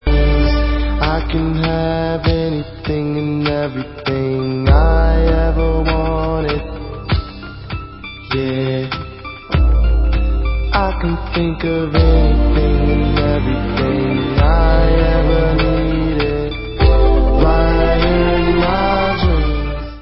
Dance